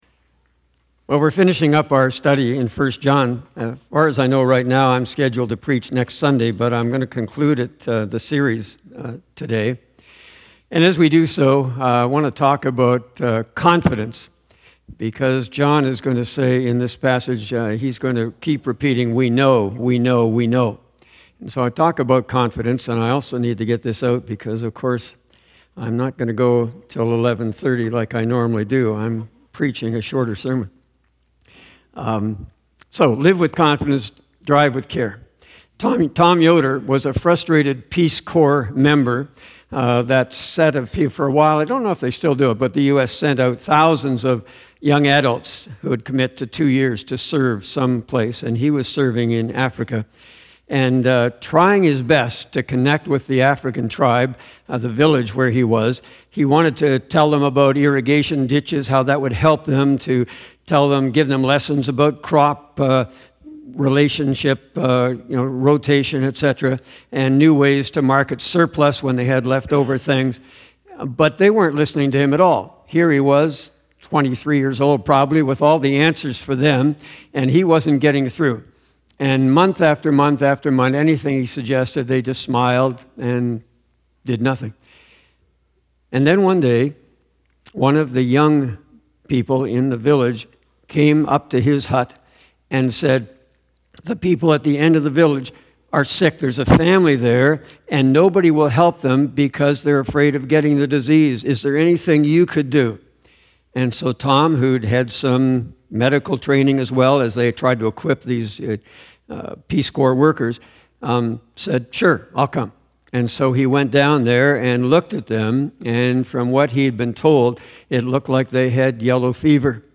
Sermons - Jacqueline Street Alliance Church